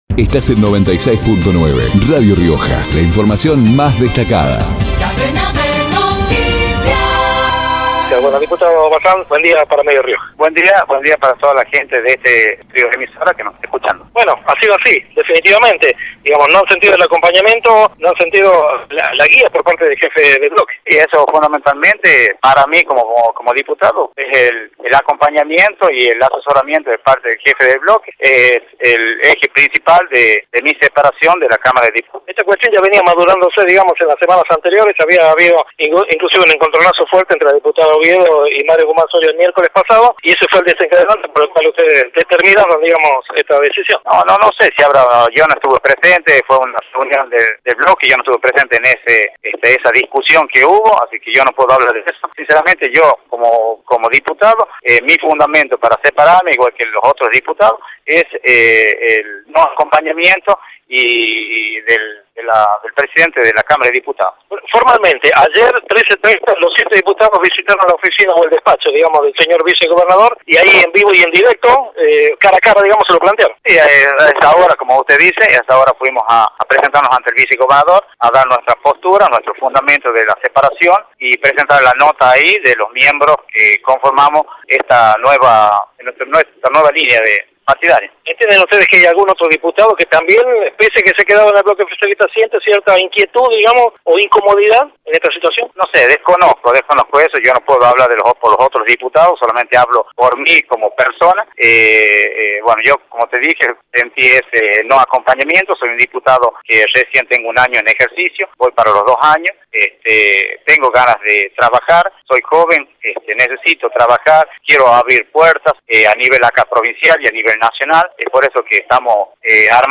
Florencio Bazán, diputado, por Radio Rioja